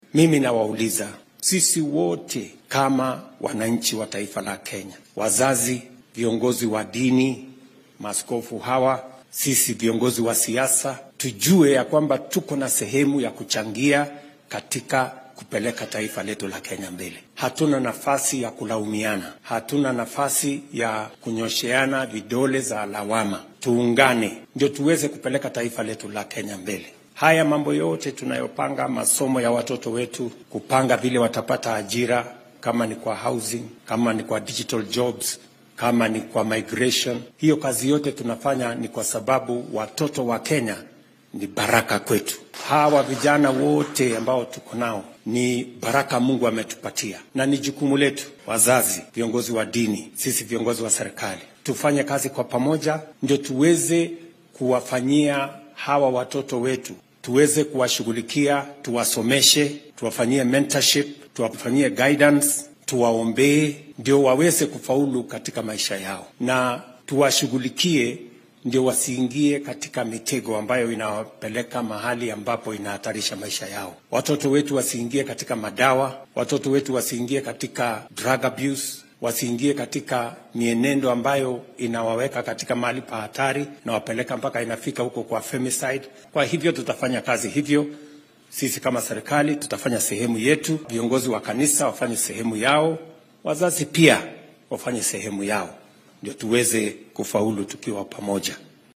Hoggaamiyaha dalka ayaa xilli uu munaasabad kaniiseed uga qayb galay ismaamulka Bomet sheegay in dhallinyarada oo si wanaagsan loo toosiyo ay ka badbaadinaysa in ay ku kacaan dhaqamada wax u dhimi kara noloshooda sida shaqaaqooyinka ay ka midka yihiin dilalka loo gaysto haweenka iyo gabdhaha iyo maamulka oo lagu gacan seyro .